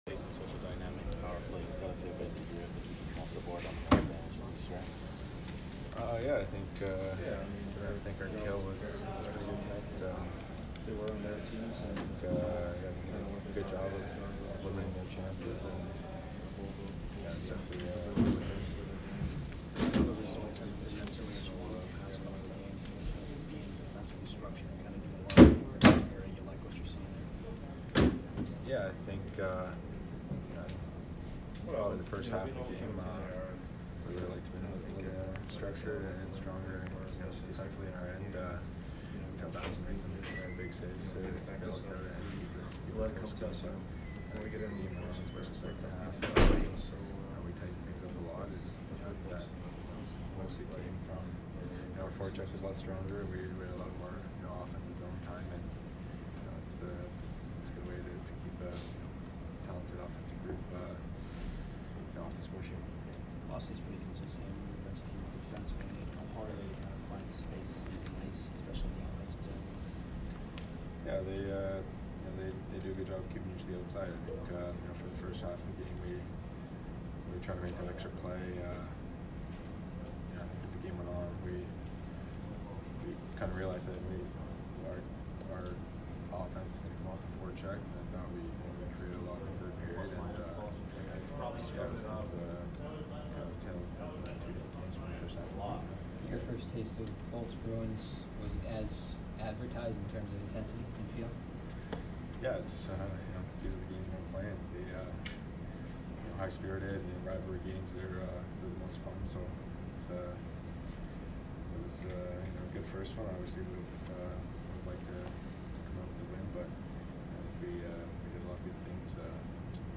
Barclay Goodrow post-game 3/3